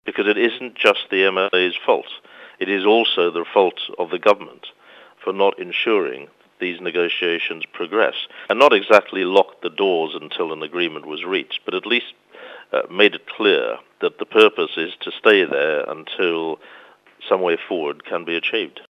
Former Secretary of State, Peter Hain, says people have all but lost confidence………..